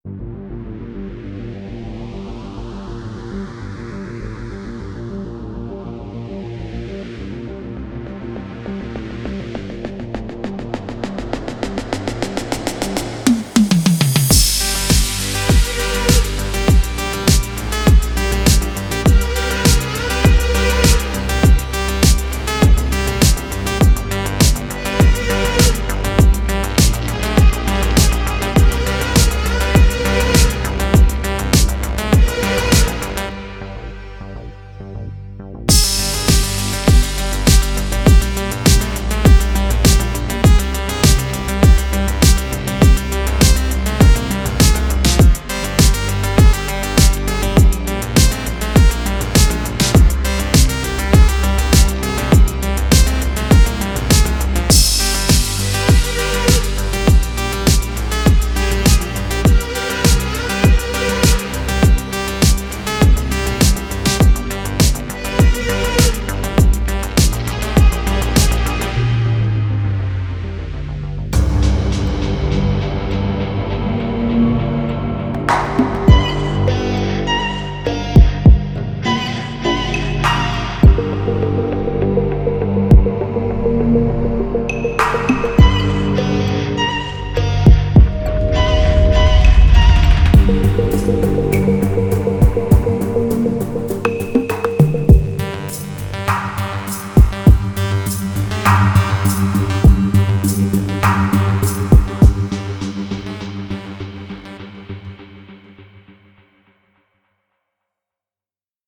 tema dizi müziği, enerjik heyecan aksiyon fon müziği.